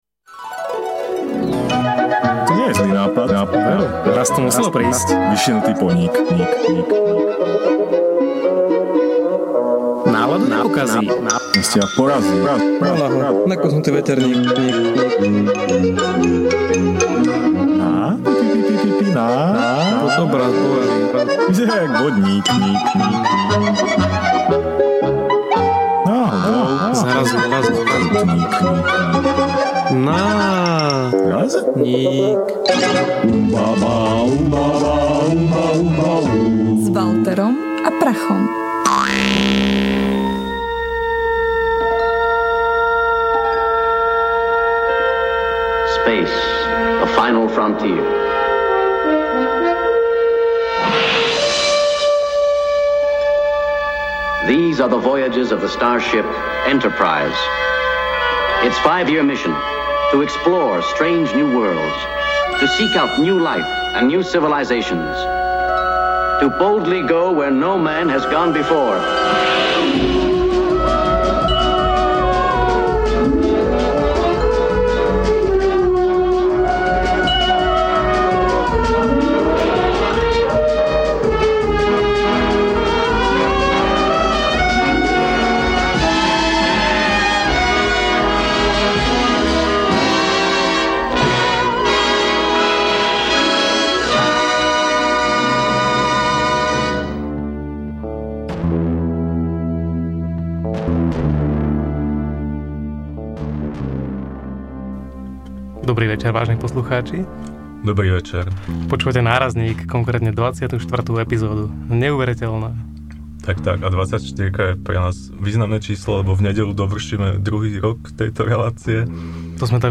NÁRAZNÍK #24 / Fríkz - Podcast NÁRAZNÍK / Ukecaná štvrtková relácia rádia TLIS - Slovenské podcasty